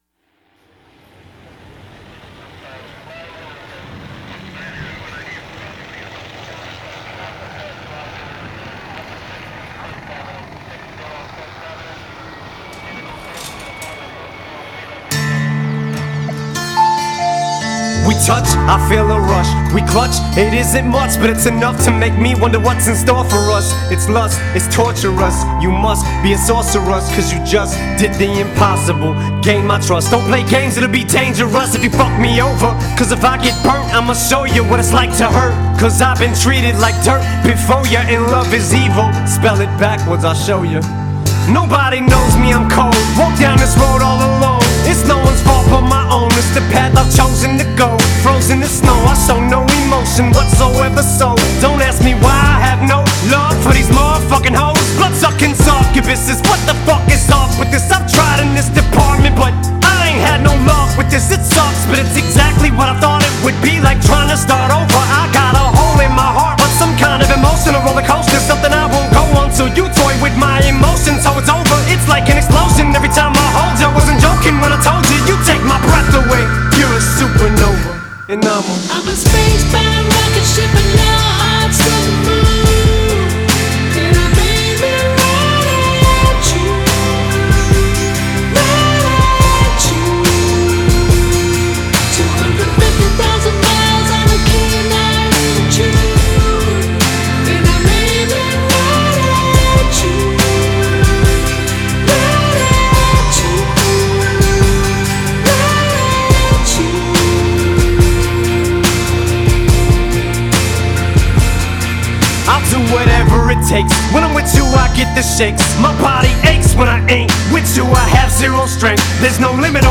Категория: Зарубежный рэп, хип-хоп